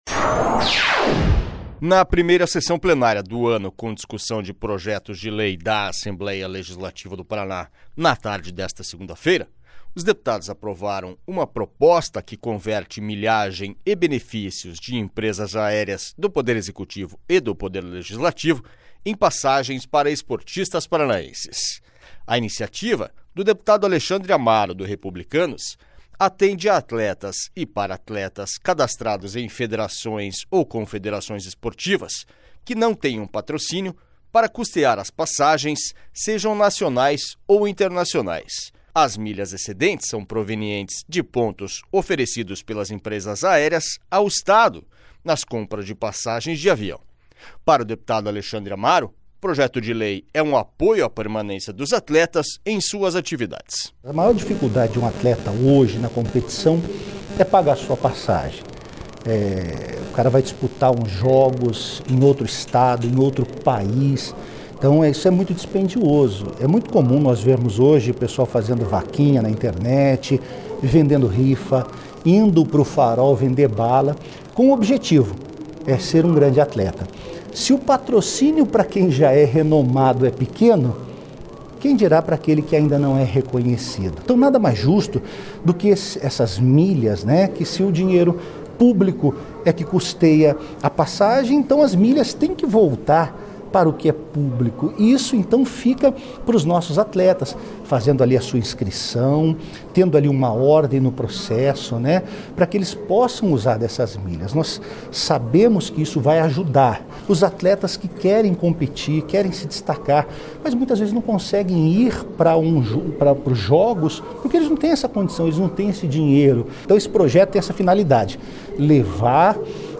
SONORA ALEXANDRE AMARO